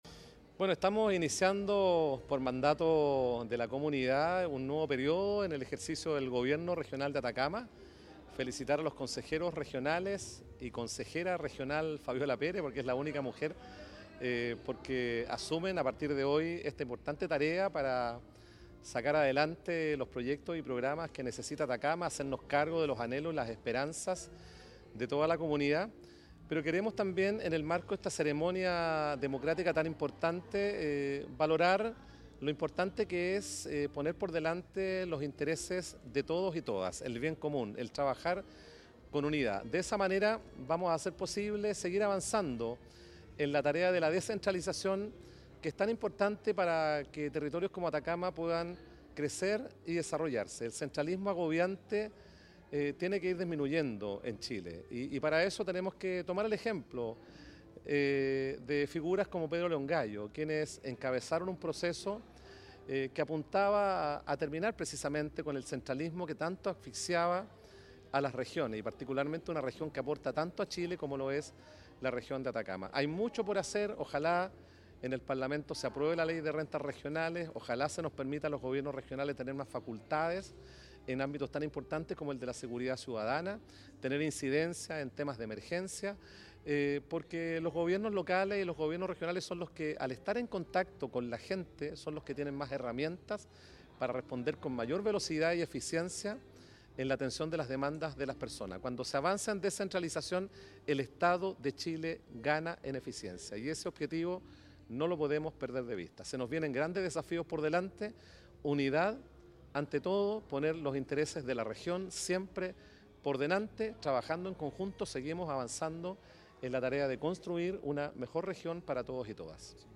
La actividad, realizada en la Sala de Cámara de Copiapó, marcó el inicio de un nuevo periodo de gestión enfocado en el desarrollo territorial y la descentralización.
GOBERNADOR-MIGUEL-VARGAS-CORREA-Ceremonia-Asuncion.mp3